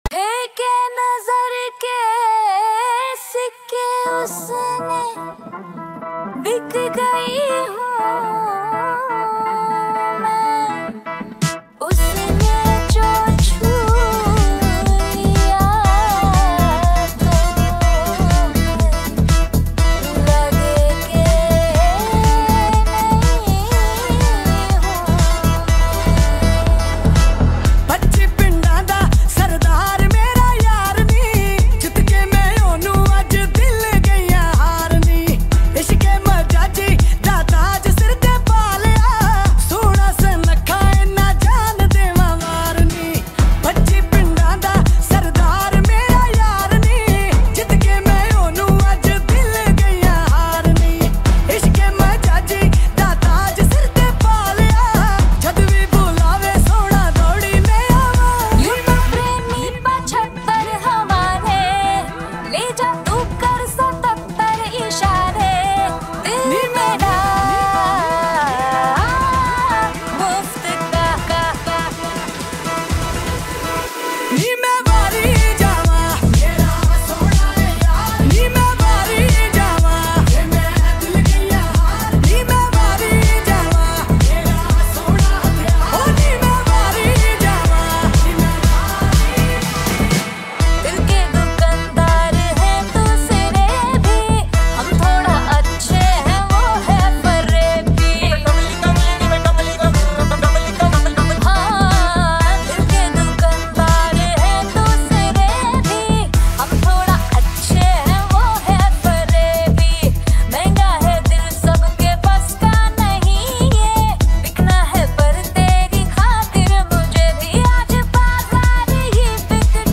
• Genre: Bollywood Mashup / DJ Remix
• Smooth and professional transitions between tracks
• Balanced bass and treble levels for clear sound output
• Enhanced beats suitable for dance environments
• Offers a balanced mix of melody and energy